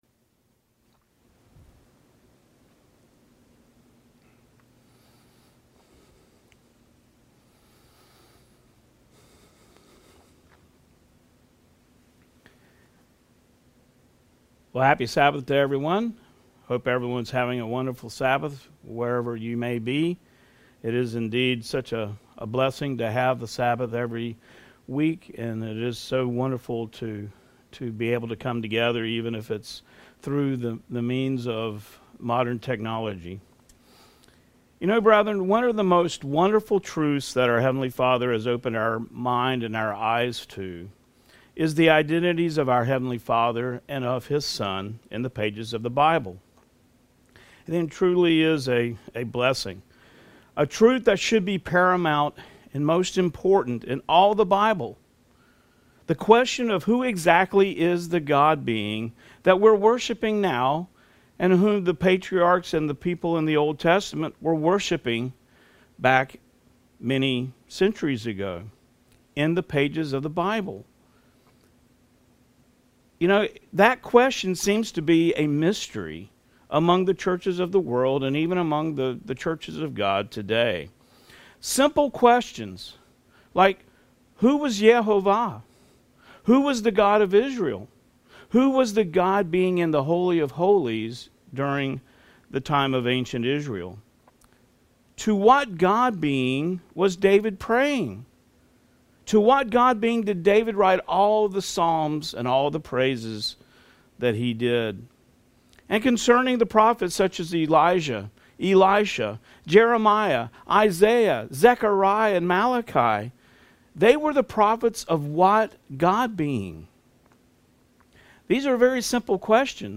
New Sermon | PacificCoG
From Location: "Houston, TX"